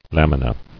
[lam·i·na]